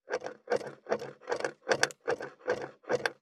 463,切る,包丁,厨房,台所,野菜切る,咀嚼音,ナイフ,調理音,まな板の上,料理,
効果音厨房/台所/レストラン/kitchen食材